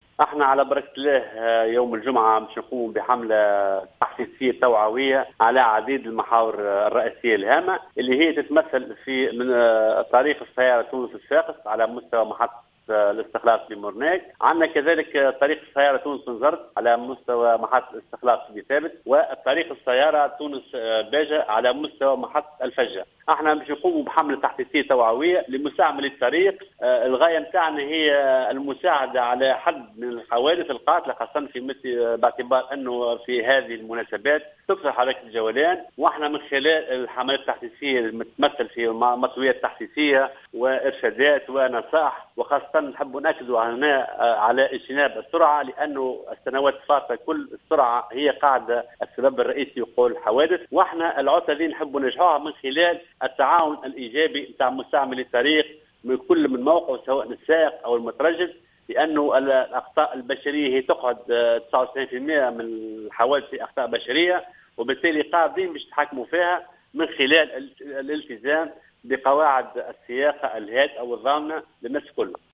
و أضاف الخماري في تصريح ل"الجوهرة أف أم" أن الحملة تتزامن مع عيد الاضحى نظرا لما تشهده الطرقات خلال هذه الفترة من حركة وكثافة مرورية.